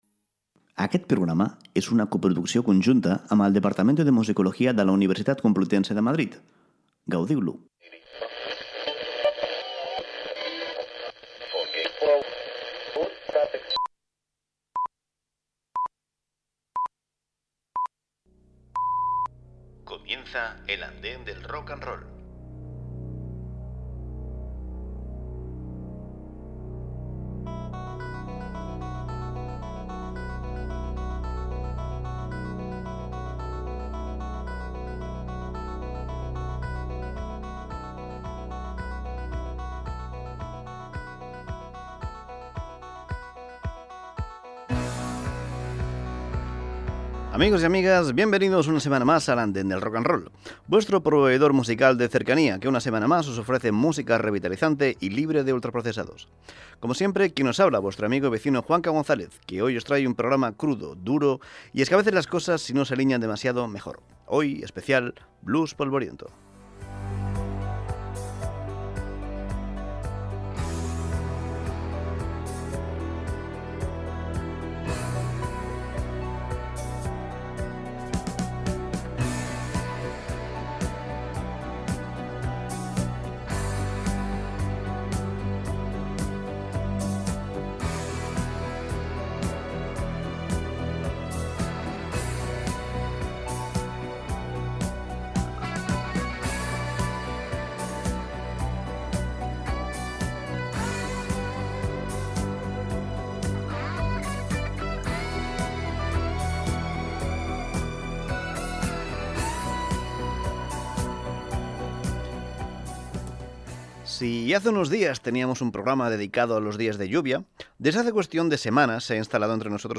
L'andana de l'Rock and Roll és un programa musical setmanal dedicat a repassar el millor de la música moderna.